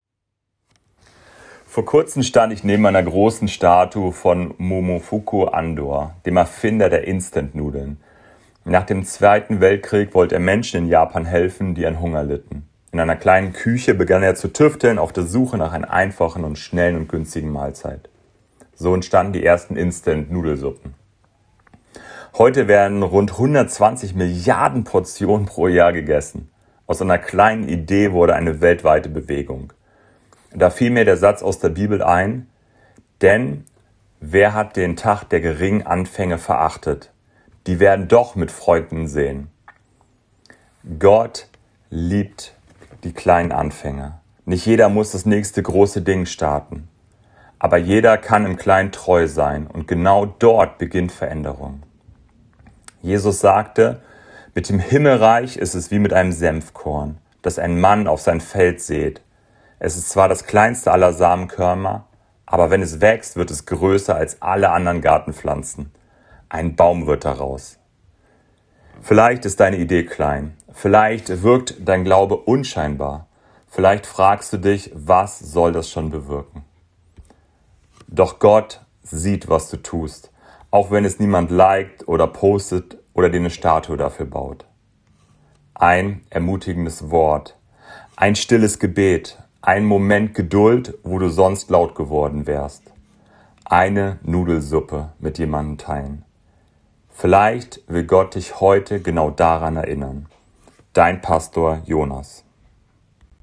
Andacht-Nudeln.m4a